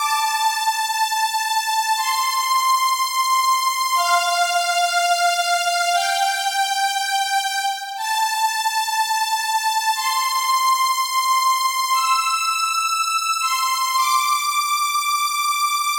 描述：一个字符串循环
Tag: 120 bpm Rap Loops Strings Loops 2.69 MB wav Key : Unknown